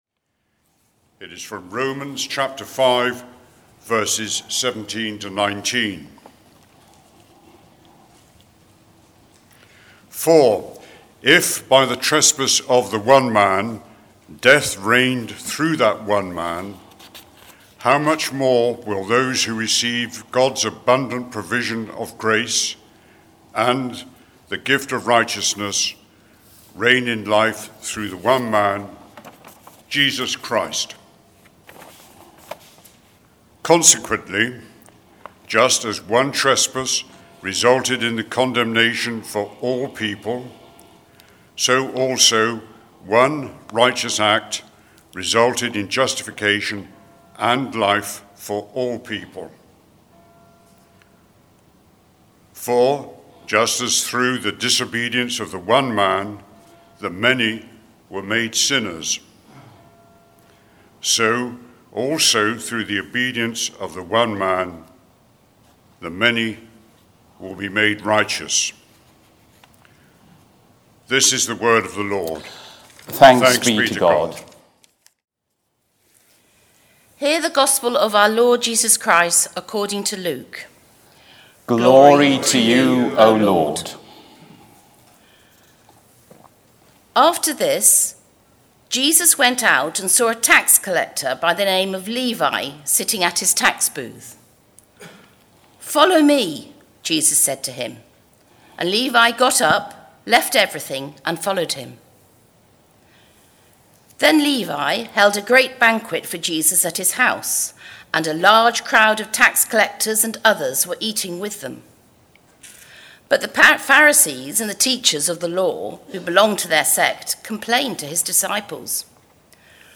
Sermon
Most sermons at St. Mary's are recorded and are available as computer files (.mp3) so that you can listen to them on your computer at home, or download them to transfer them to your portable music player (eg iPod).